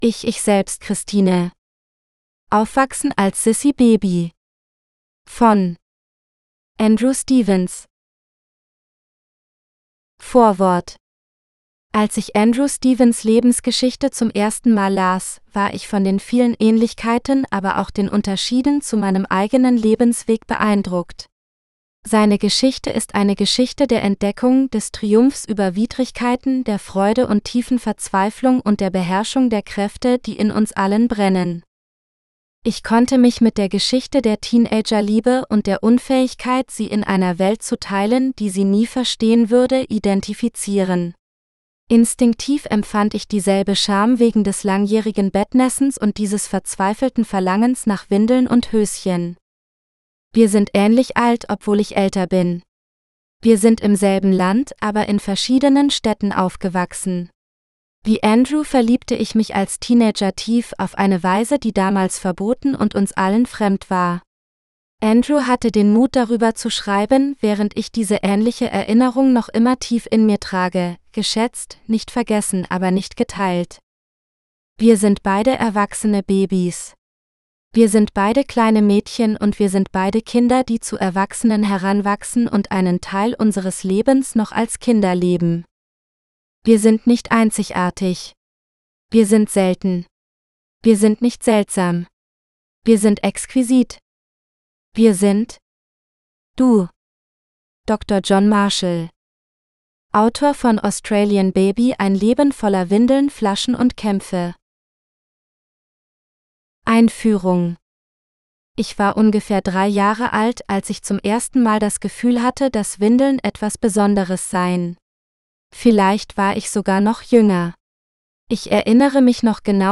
Me, Myself, Christine GERMAN (AUDIOBOOK – female): $US5.75